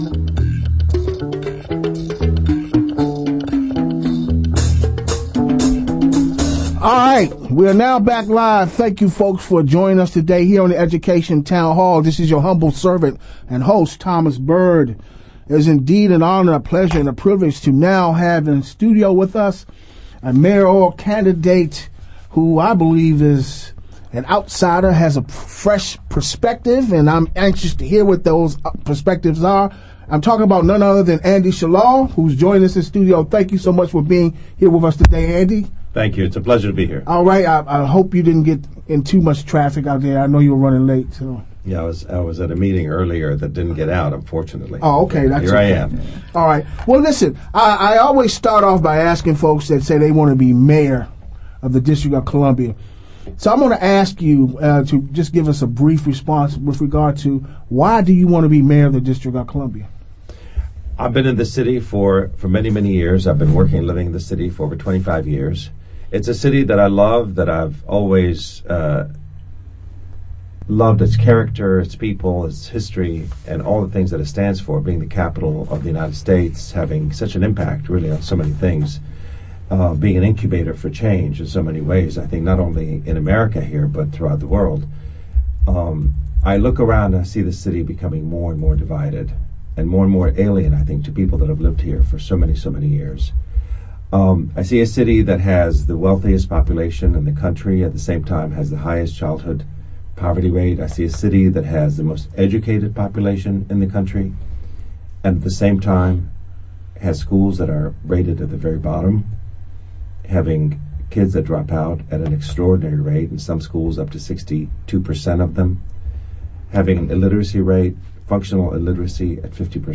Listen to the full discussion on January 9 edition of the Education Town Hall.